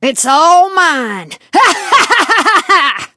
belle_lead_vo_02.ogg